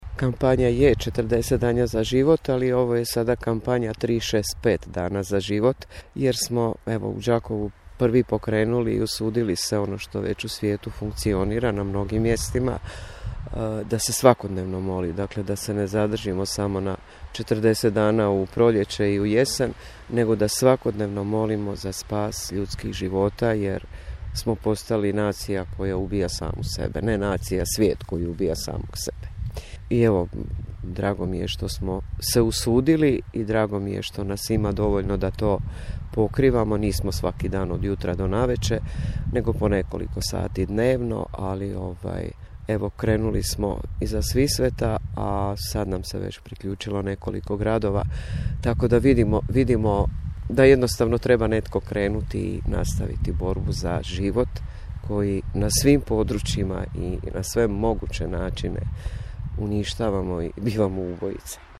Ni debeli minusi nisu spriječili članove Inicijative 40 dana za život da mole za nerođenu djecu. Tako smo i danas kod Doma zdravlja u Strossmayerovom parku, kada se temperatura spustila ispod nule, zatekli jednu našu sugrađanku s krunicom u ruci.